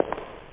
Amiga 8-bit Sampled Voice
footstepecho.mp3